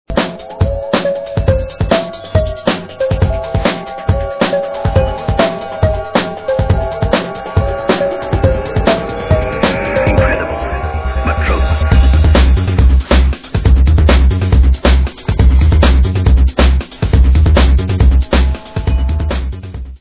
sledovat novinky v oddělení Dance/Drum & Bass